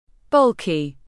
Bulky /ˈbʌl·ki/